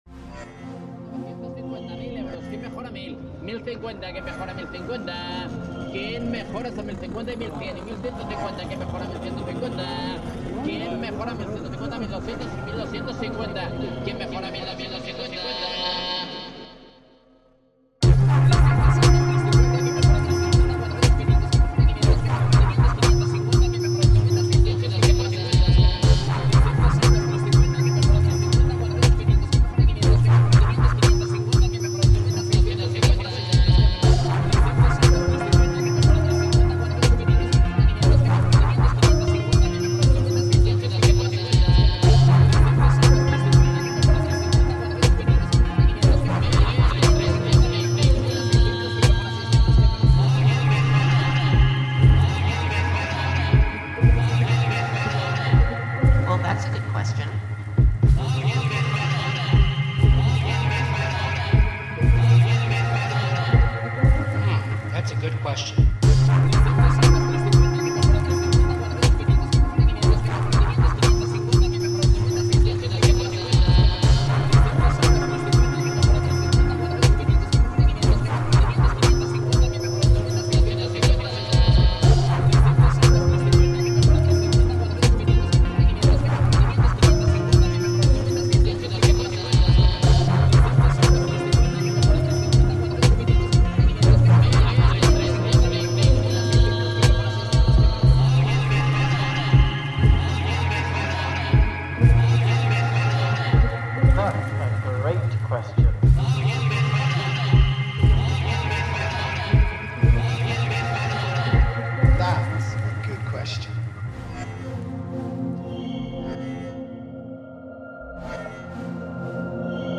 Flea market in Barcelona reimagined